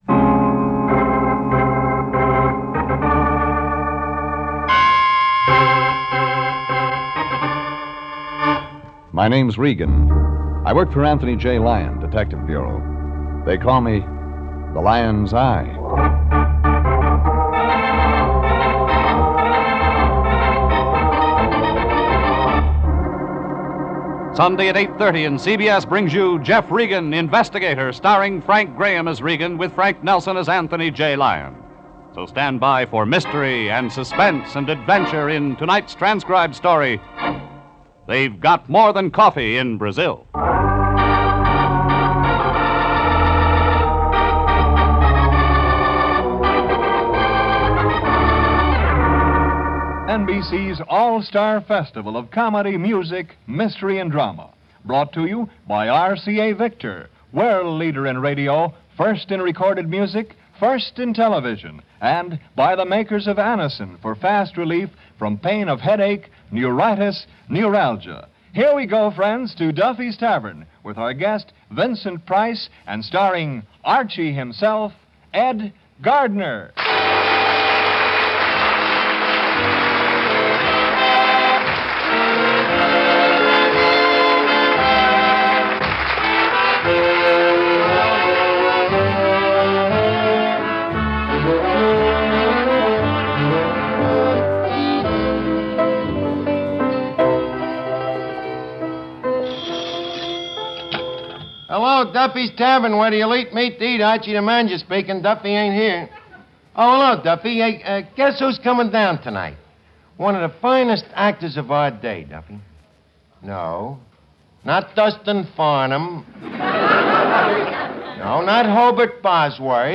You'll find rare and obscure as well as mainstream radio shows from the 1930s, 1940s, and 1950s in the Radio Archives Treasures sets. These shows have all been restored with state-of-the-art CEDAR technology - the audio processing system used by major recording companies to restore older recordings. We expect the shows to be the best sounding copies available anywhere.